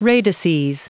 Prononciation du mot radices en anglais (fichier audio)
Prononciation du mot : radices